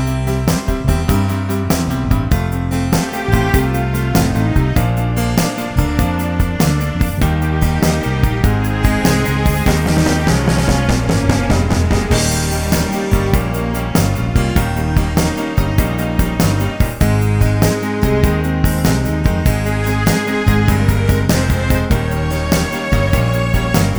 no Backing Vocals Rock 'n' Roll 4:01 Buy £1.50